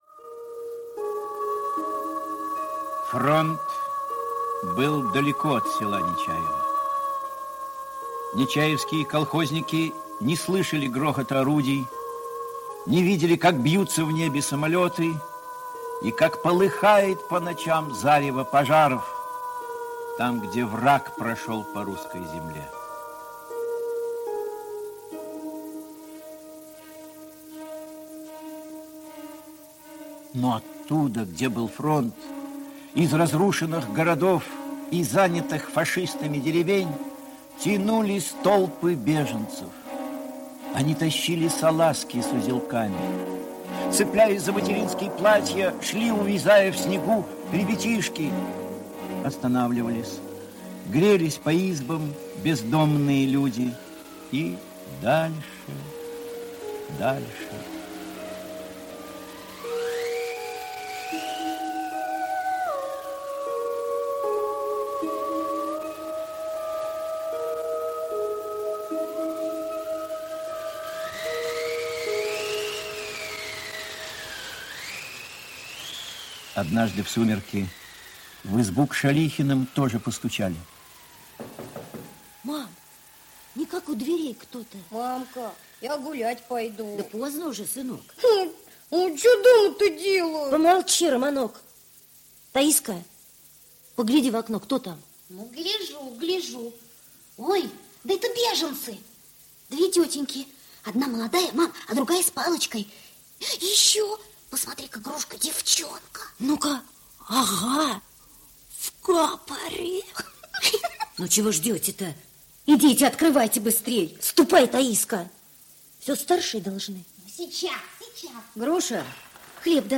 Девочка из города - аудио повесть Воронковой - слушать